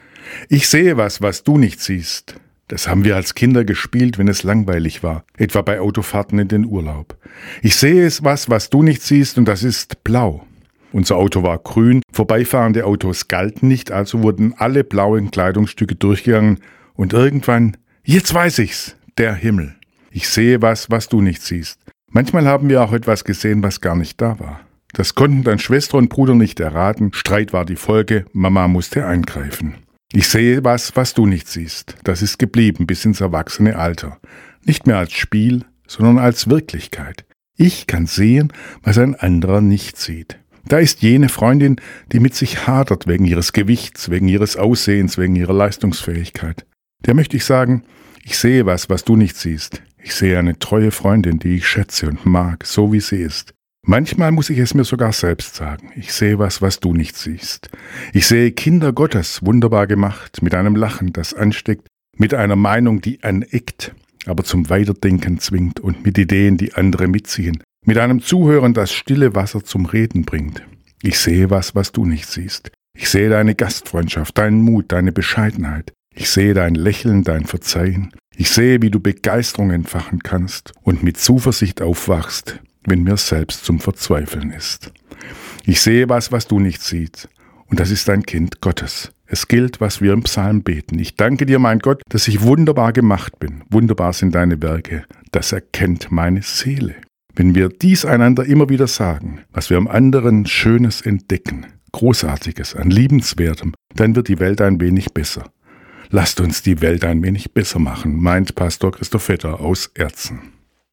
Radioandacht vom 28. Mai